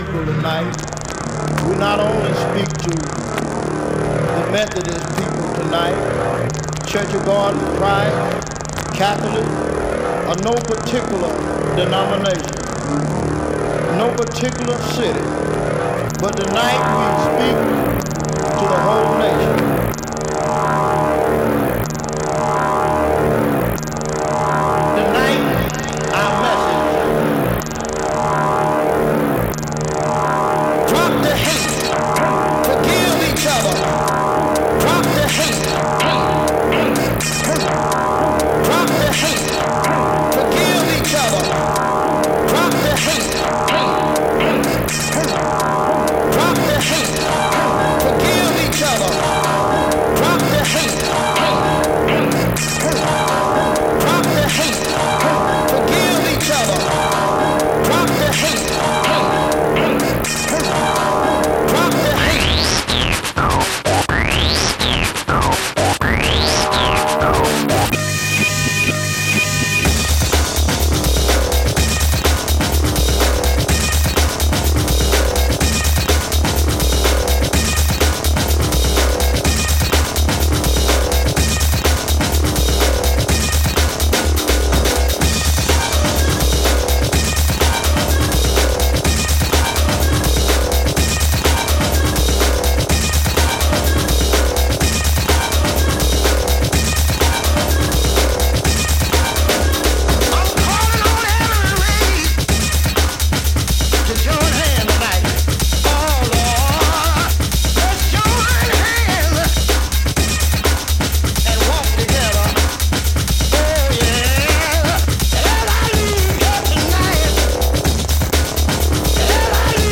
Styl: House, Techno, Breaks/Breakbeat